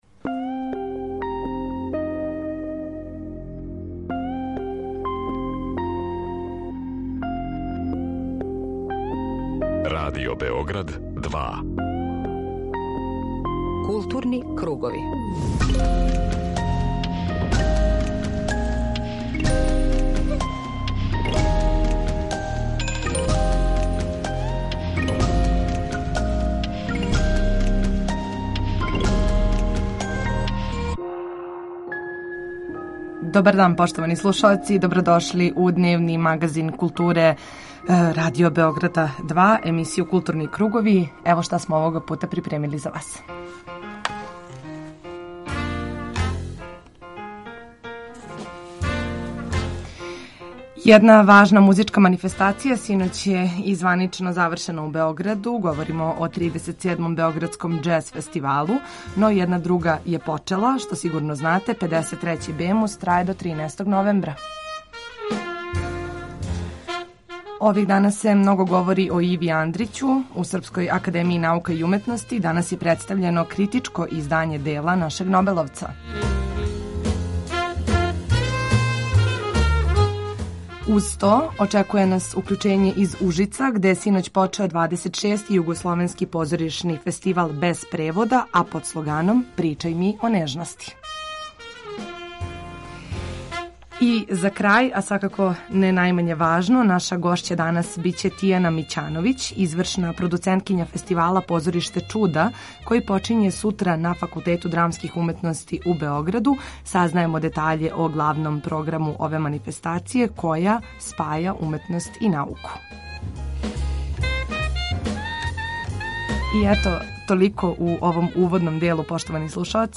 Очекује нас укључење из Ужица где је синоћ отворен 26. Југословенски позоришни фестивал „Без превода" који се игра под мотом „Причај ми о нежности".